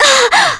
Frey-Vox_Damage_kr_02.wav